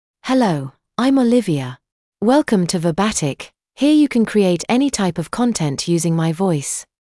FemaleEnglish (United Kingdom)
Olivia is a female AI voice for English (United Kingdom).
Voice sample
Female
Olivia delivers clear pronunciation with authentic United Kingdom English intonation, making your content sound professionally produced.